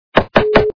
При прослушивании Стук - В дверь качество понижено и присутствуют гудки.
Звук Стук - В дверь